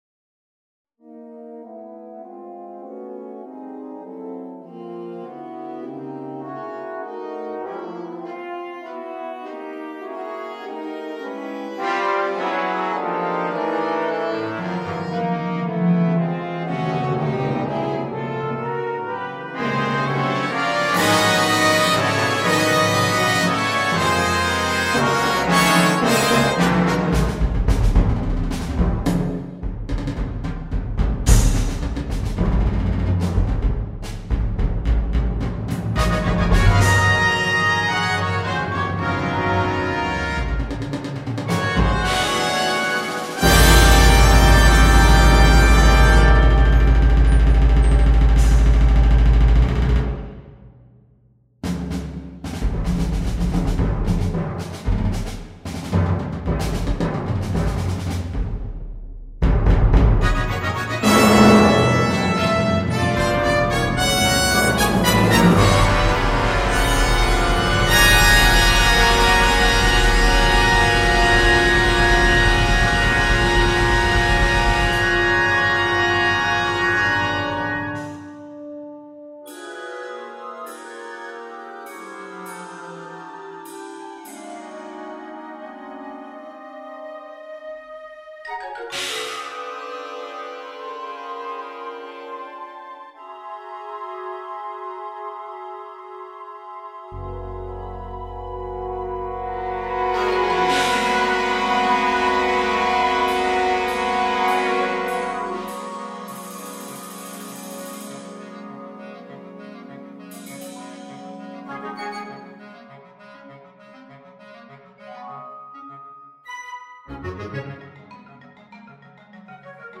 Pour orchestres d’harmonie, version de la première mondiale
For harmony orchestras, world premiere version.
Piccolo flute / Flute Piccolo
Oboe /Hautbois
Eb Alto saxophones/ Saxophones altos 1 et 2 en mib
Bb Trumpets 1 &2 / Trompettes en sib 1, 2 et 3
French horns in F / Cors d’harmonie en fa 1-3, 2-4
Tubas
Electric bass/ Basse électrique
2° Marimba plays xylophone / Marimba joue xylophone
5° Rock drumset/ Batterie rock